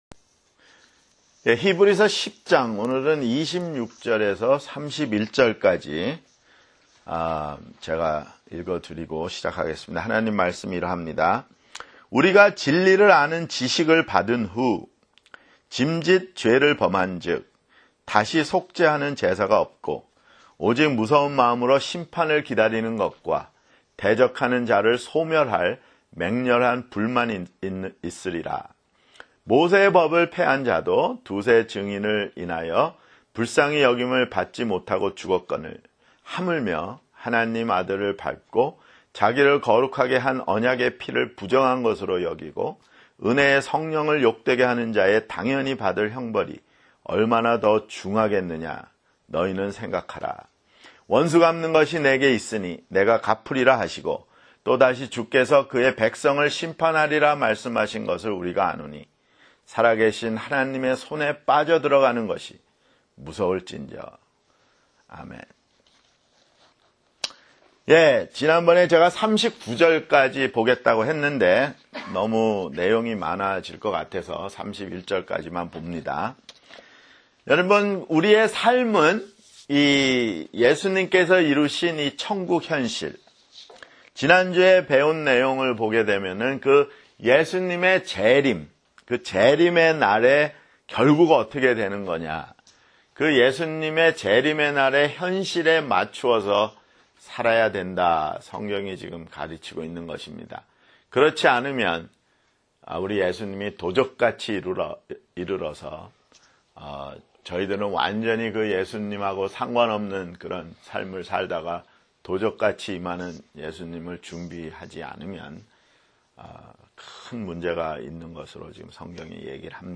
[성경공부] 히브리서 (34)